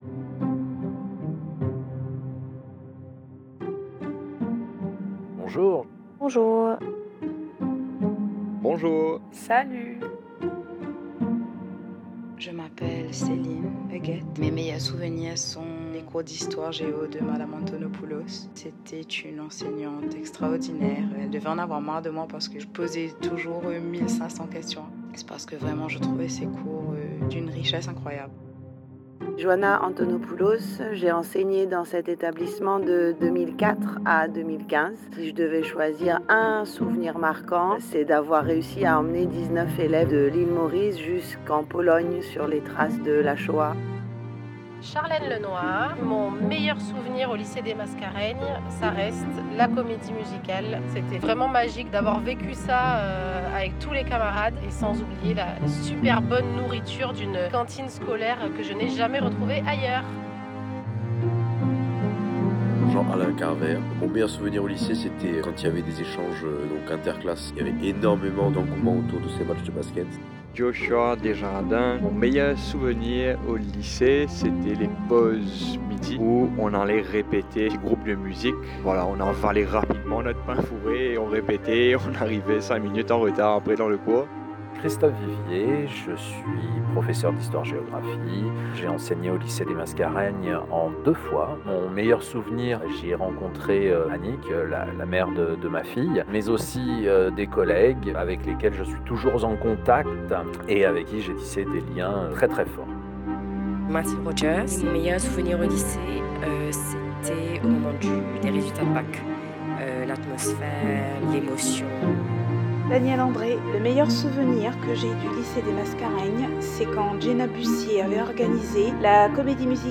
Dans la comédie musicale 25ème Porte, la bande-son « Mo Rappel » réunit les voix d’anciens élèves et anciens personnels, donnant vie à un extrait émouvant et plein d’énergie. Ce morceau original prolonge l’expérience du spectacle en mêlant souvenirs et créativité, et célèbre le lien unique qui unit la communauté du lycée.